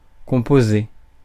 Ääntäminen
France: IPA: [kɔ̃.po.ze]